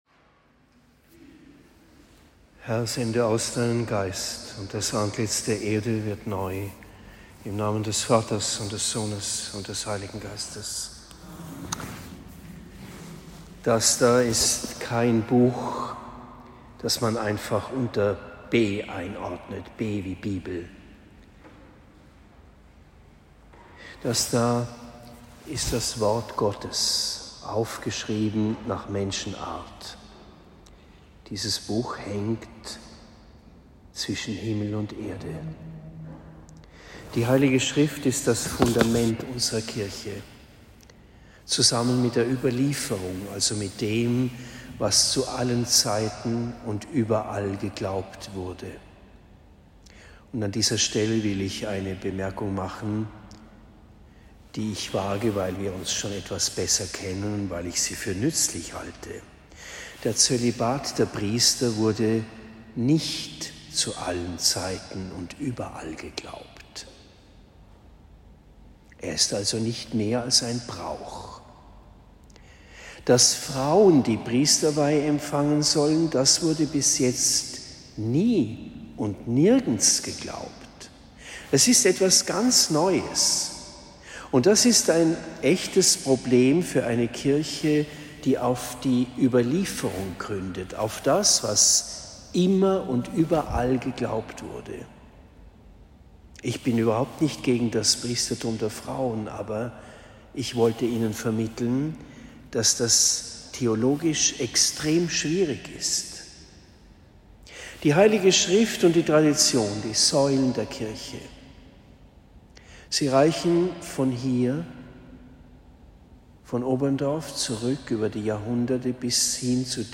Predigt in Oberndorf im Spessart am 17. Februar 2023